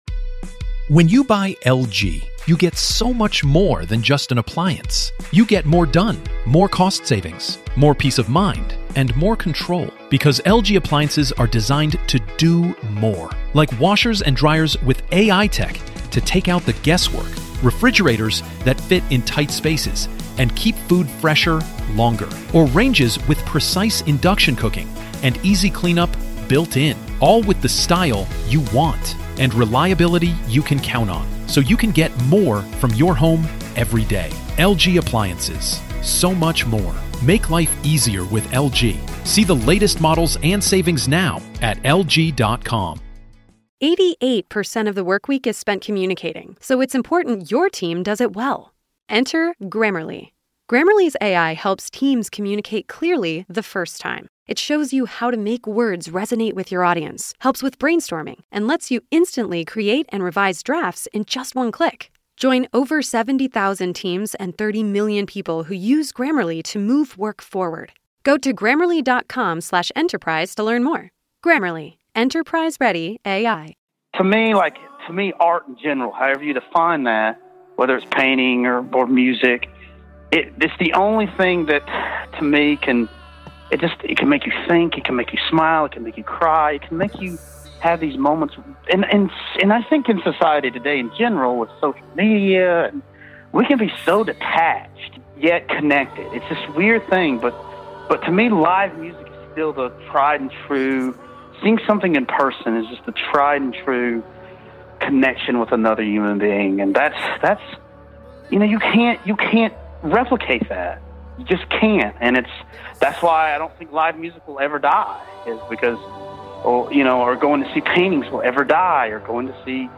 Paul is the lead singer of St. Paul and The Broken Bones, the incredible six-piece soul band from Birmingham, Alabama. We talk about music, faith, race, art, books, and everything in-between.